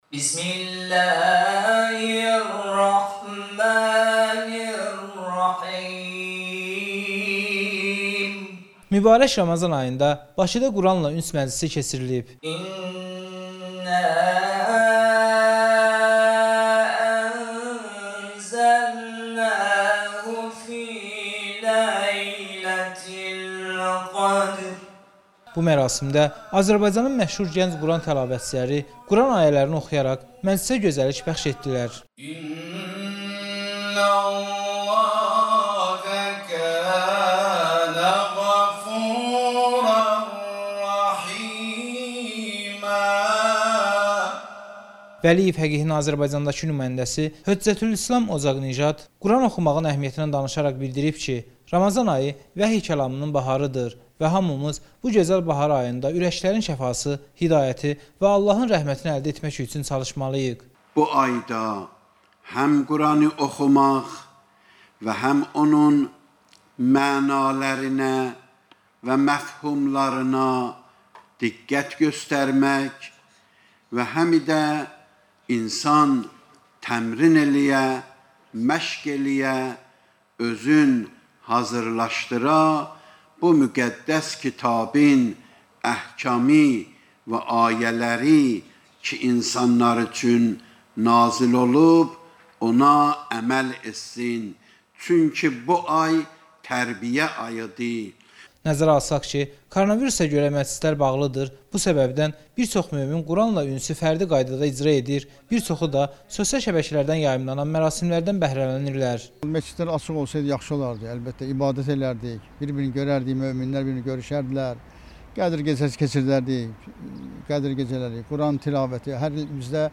Azərbaycanın tanınmış Quran Qarilərinin iştrakı ilə Bakıda quranla üns məclisi keçirilib